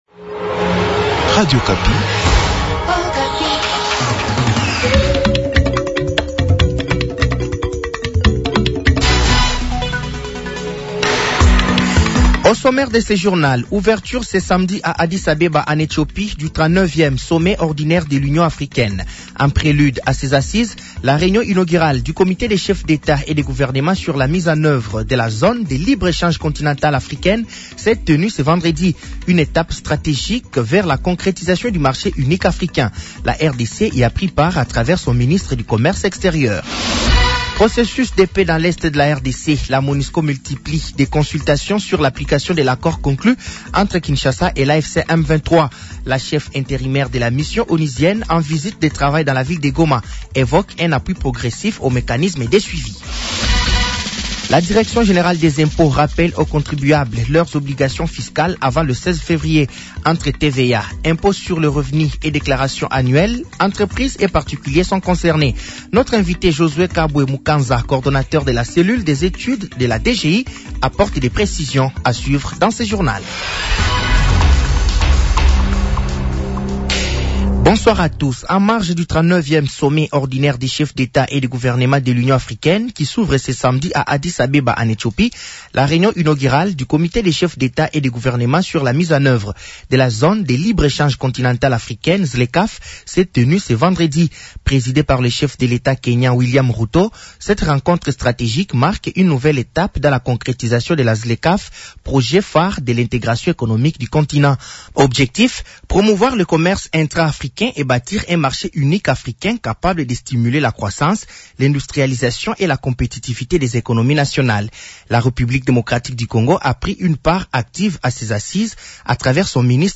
Journal français de 18h de ce samedi 14 février 2026